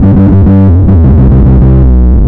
FINGERBSS6-R.wav